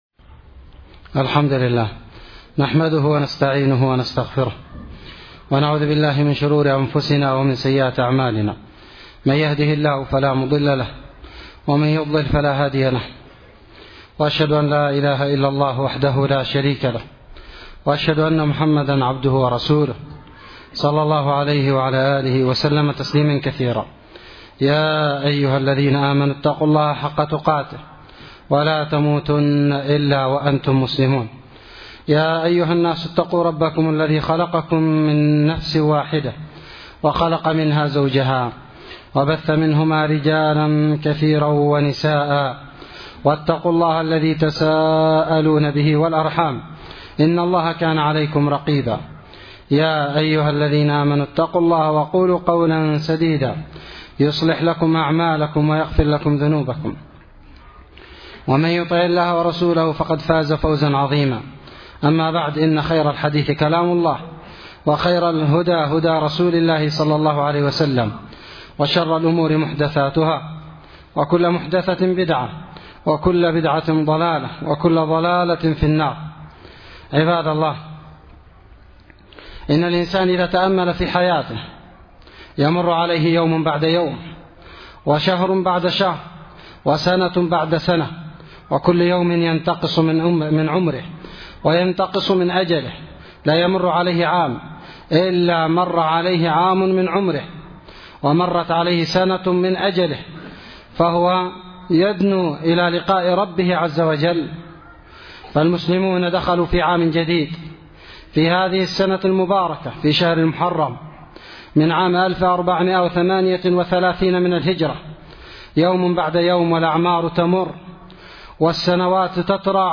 خطبة بعنوان: (( محاسبة النفوس )) 6 محرم 1438هـ